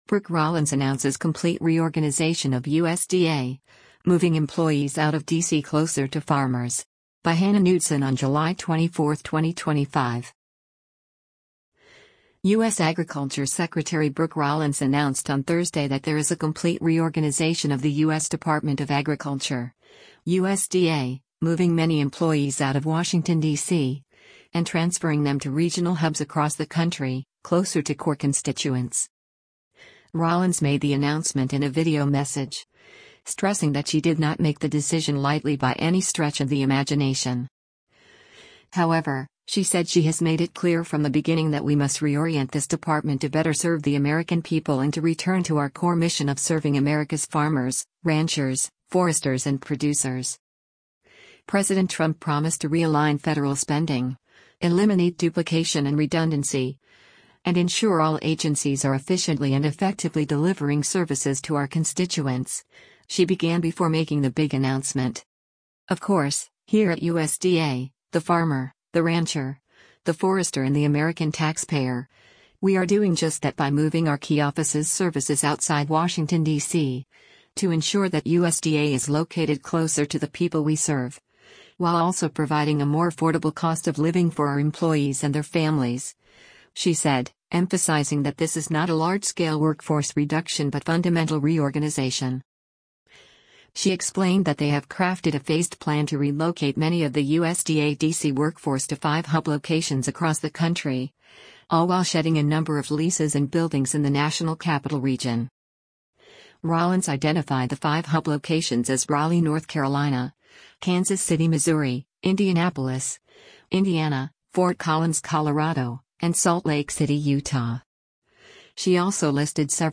Rollins made the announcement in a video message, stressing that she did not make the decision lightly by any stretch of the imagination.